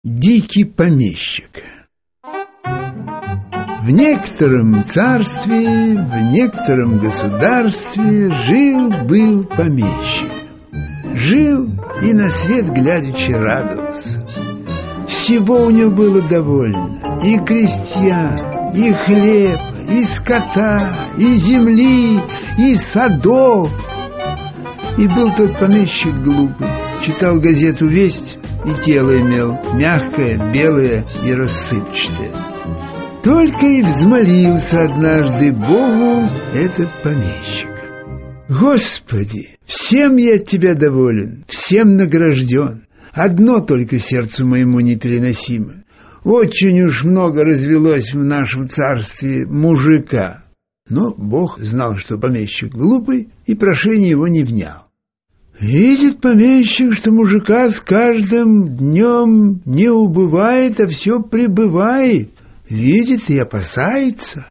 Аудиокнига Сказки | Библиотека аудиокниг
Aудиокнига Сказки Автор Михаил Салтыков-Щедрин Читает аудиокнигу Михаил Ульянов.